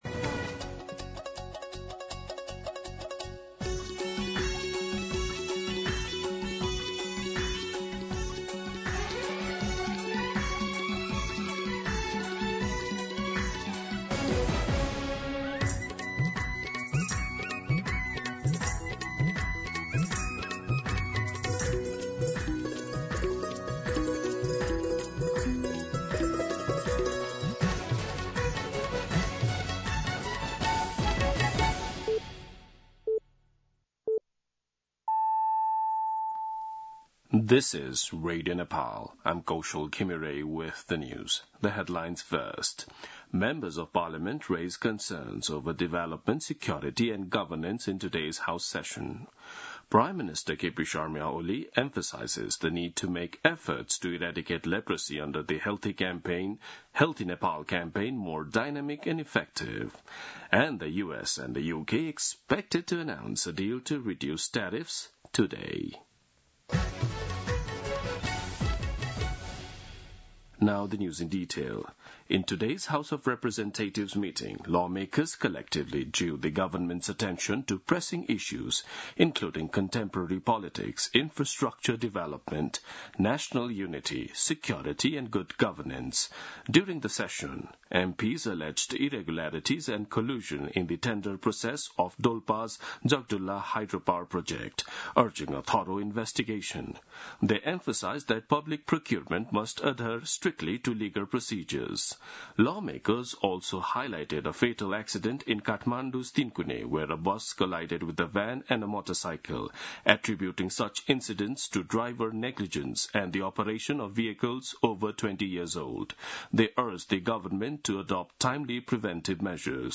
दिउँसो २ बजेको अङ्ग्रेजी समाचार : २५ वैशाख , २०८२
2-pm-English-News-1.mp3